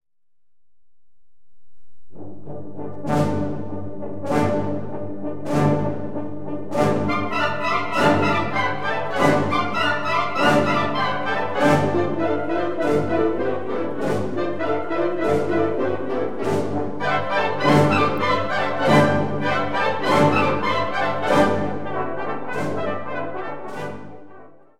Category Concert/wind/brass band
Subcategory Suite
Instrumentation Ha (concert/wind band)